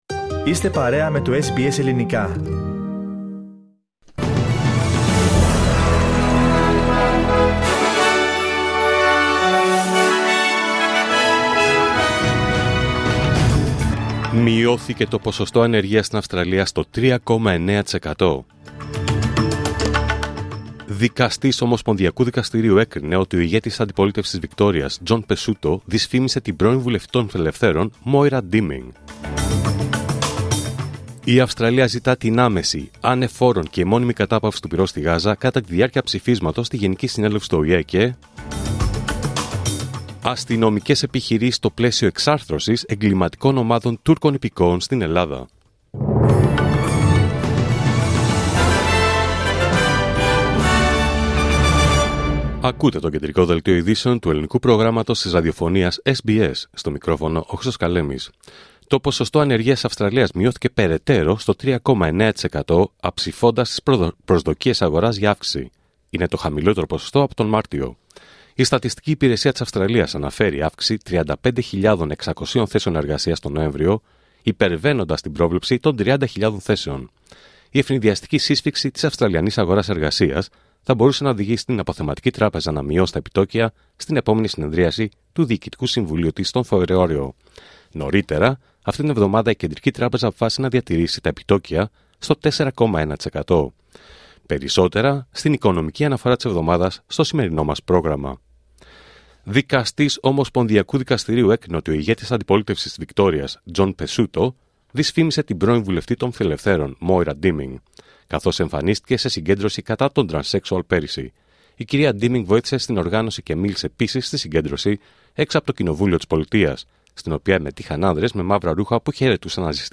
Δελτίο Ειδήσεων Πέμπτη 12 Δεκέμβριου 2024